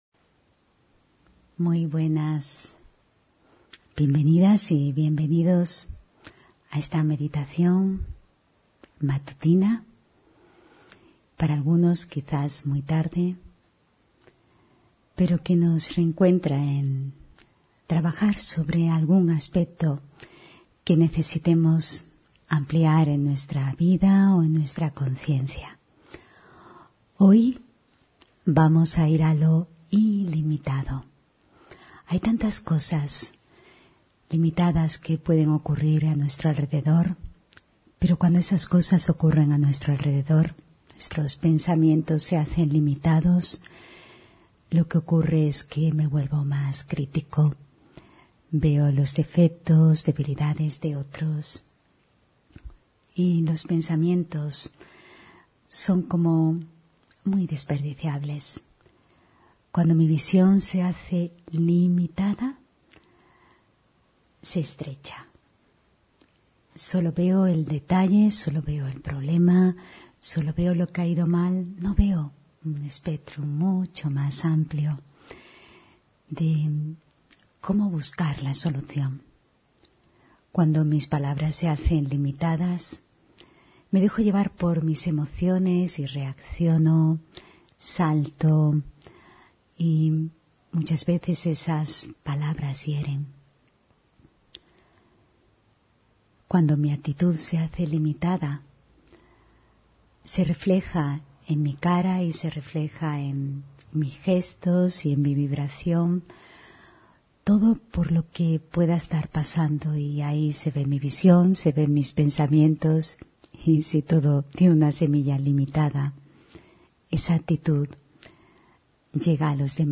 Meditación de la mañana: Ir a lo ilimitado